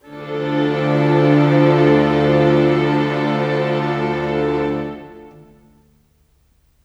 Pad Dmin 01.wav